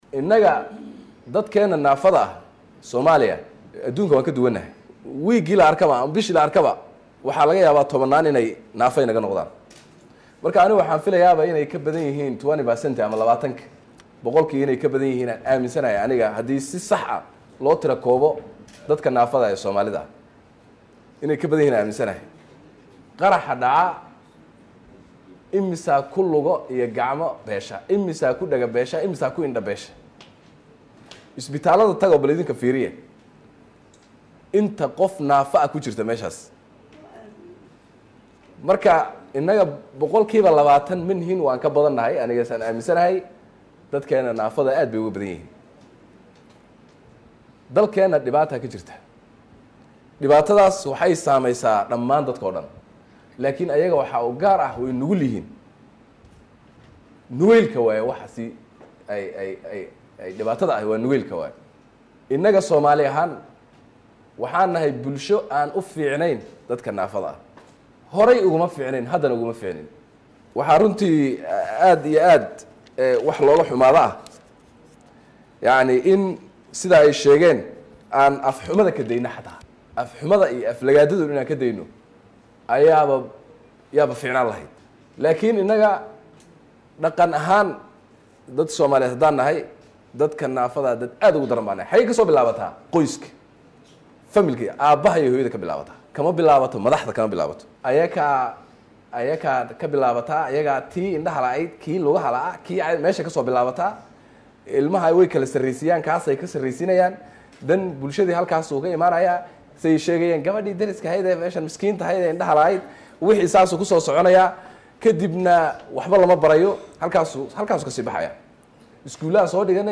Hadalkan ayuu Ra’iisul Wasaaraha ka jeediyay munaasabad lagu xusayay Maalinta Caalamiga ah ee dadka qaba baahiyaha gaarka ah.